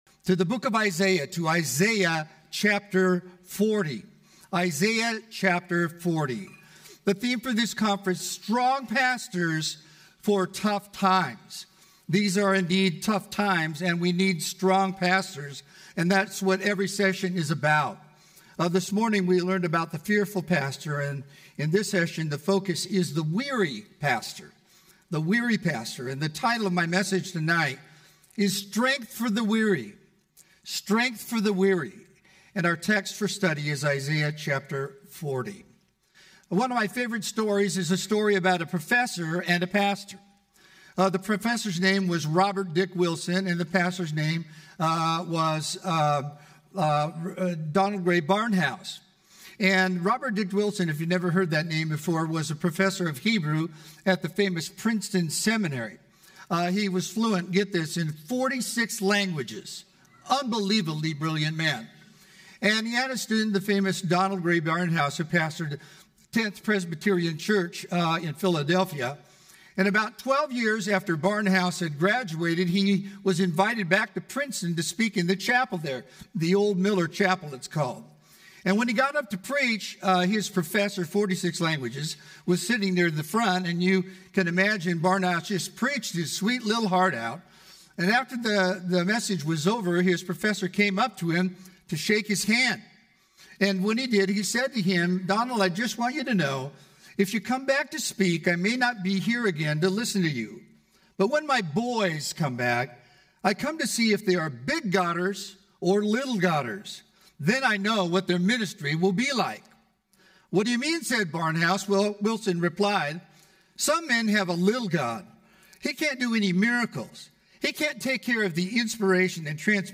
Home » Sermons » “The Weary Pastor”